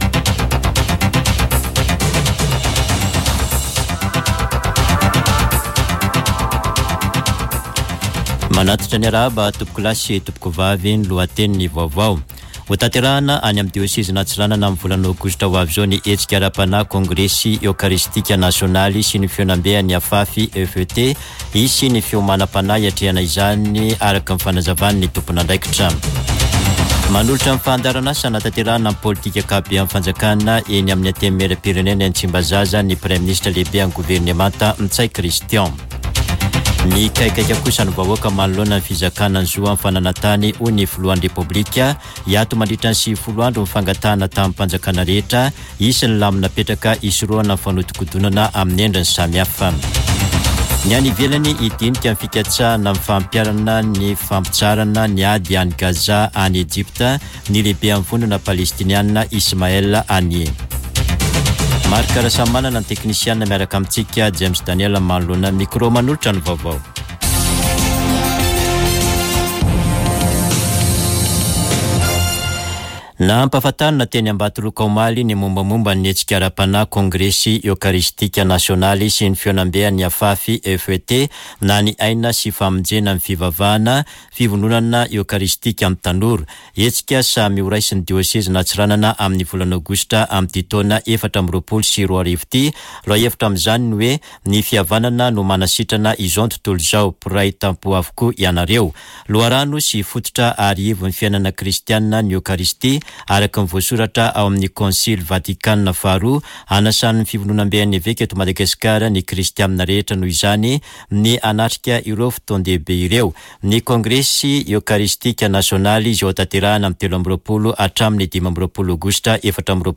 [Vaovao antoandro] Alakamisy 01 febroary 2024